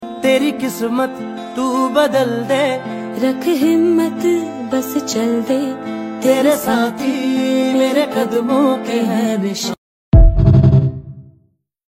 📢 Current Affairs Lecture at sound effects free download